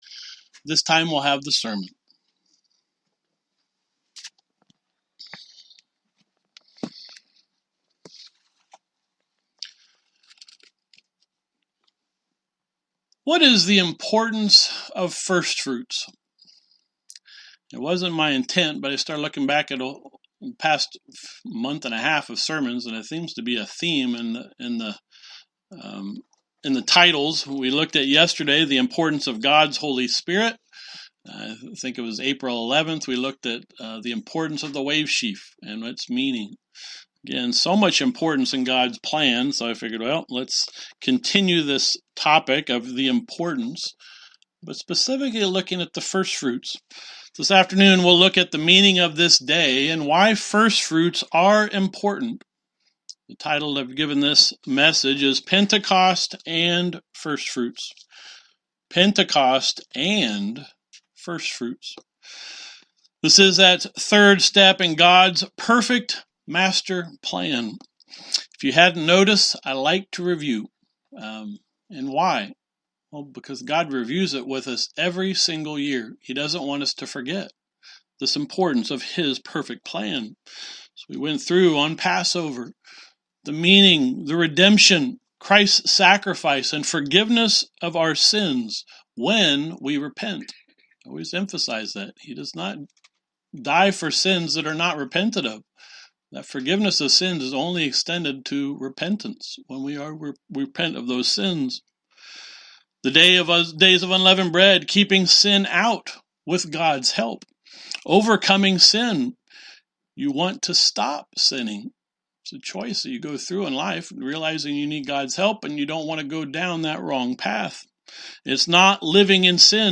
Sermons
Given in Elkhart, IN Northwest Indiana